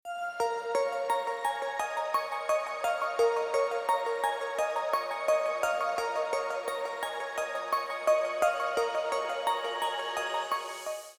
• Качество: 320, Stereo
спокойные
без слов
Chill
Melodic